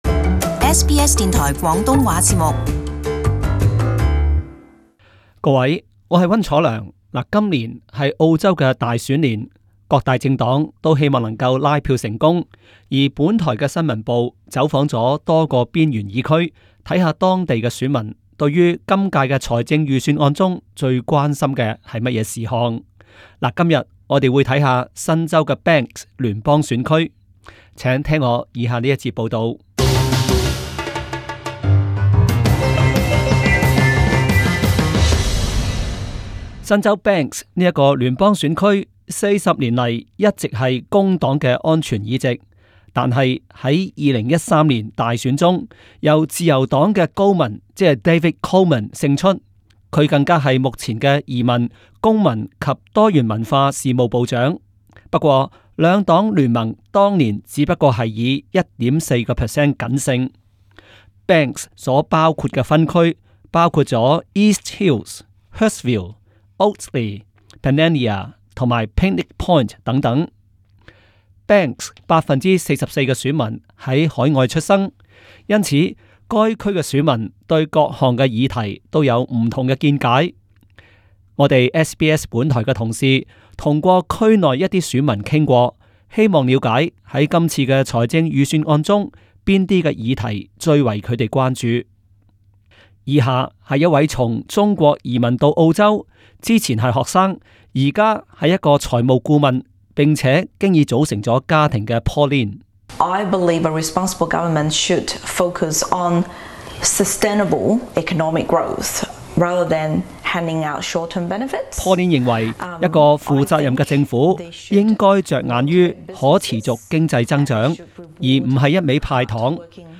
今年是澳洲大选年，各大政党都希望可以成功拉票，而下周二揭晓的联邦财政预算案，甚爲选民关心。因此，本台新闻报走访多个联邦边缘选区，看看当地选民对于哪些事项特别关注。